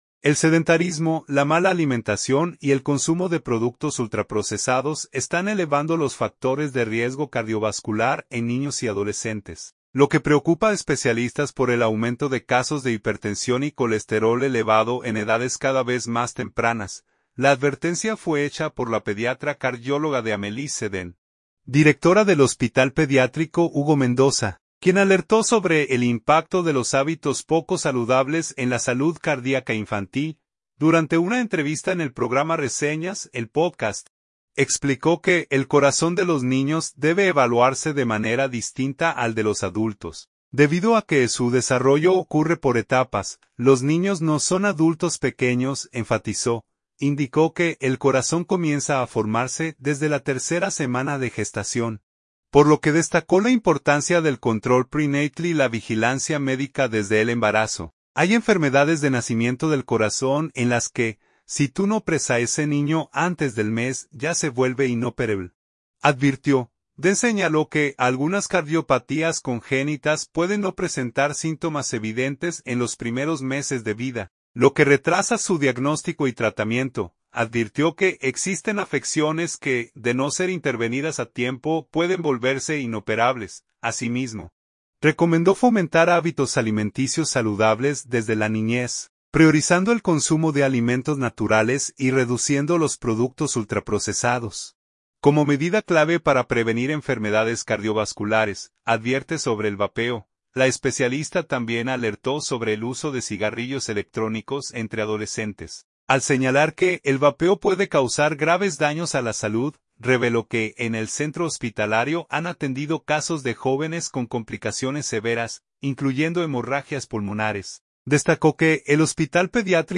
Durante una entrevista en el programa Reseñas, el podcast, explicó que el corazón de los niños debe evaluarse de manera distinta al de los adultos, debido a que su desarrollo ocurre por etapas.